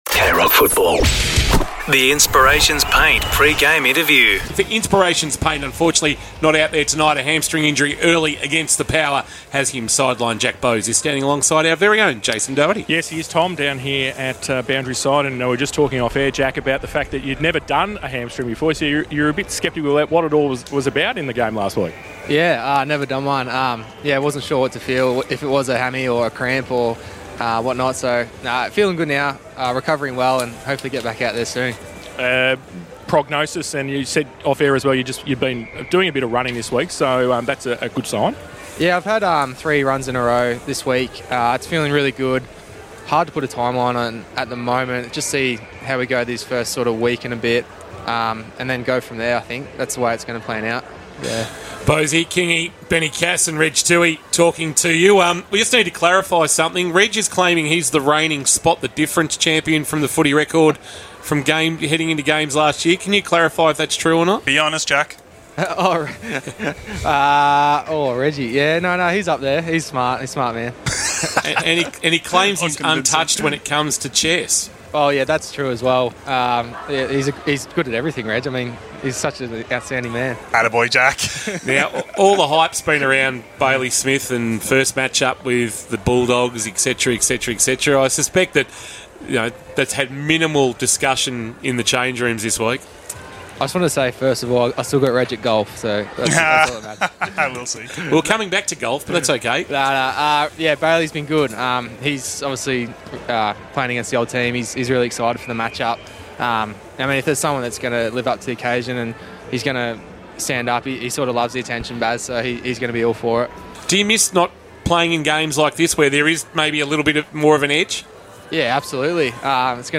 2025 - AFL - Round 11 - Geelong vs. Western Bulldogs: Pre-match interview - Jack Bowes (Geelong Cats)